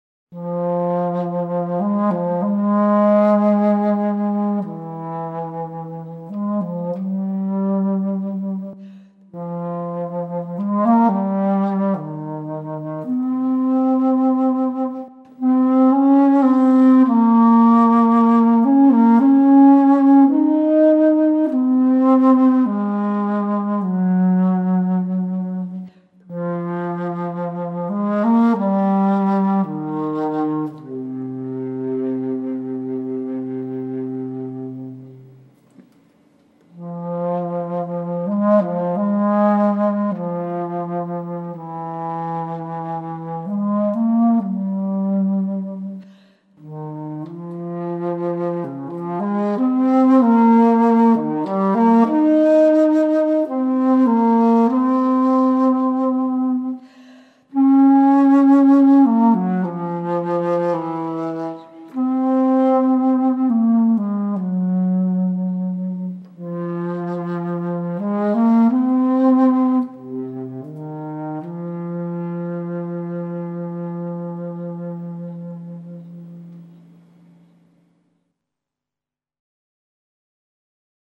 flutes